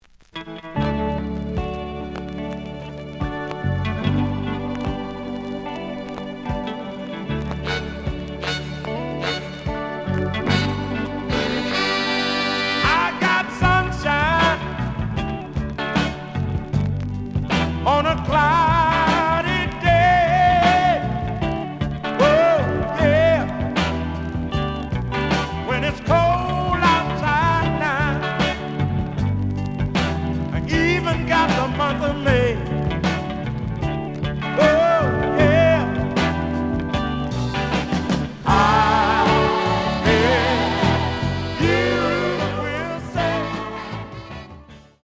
The audio sample is recorded from the actual item.
●Genre: Soul, 70's Soul
Some click noise on beginning of A side due to scratches.